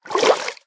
swim3.ogg